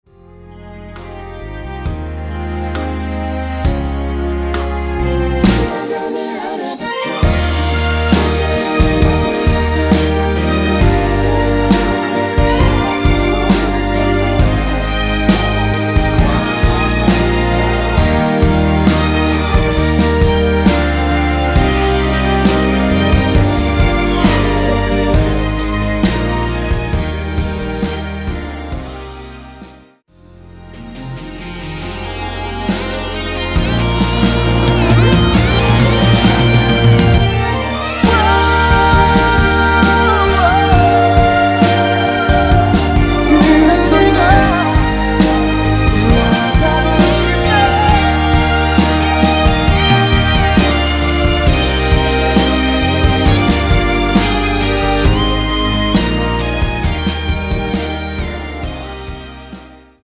원키 코러스 포함된 MR입니다.
Eb
앞부분30초, 뒷부분30초씩 편집해서 올려 드리고 있습니다.